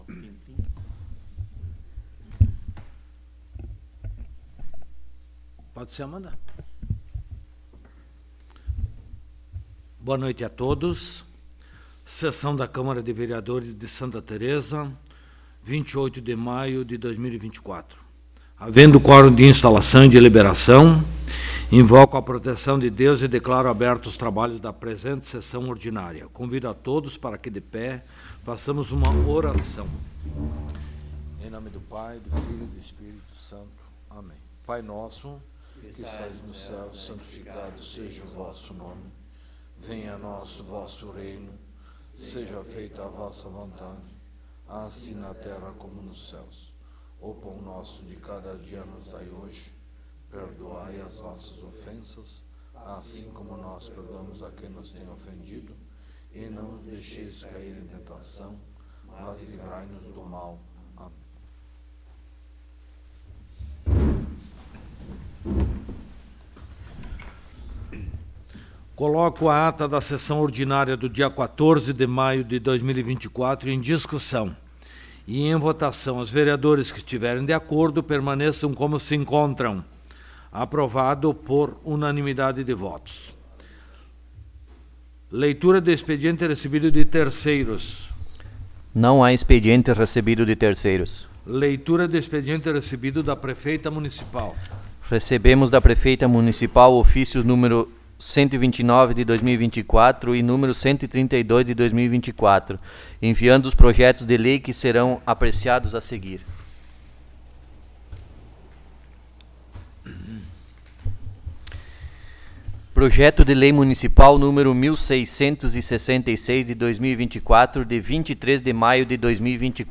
8° Sessão Ordinária de 2024
Local: Câmara Municipal de Vereadores de Santa Tereza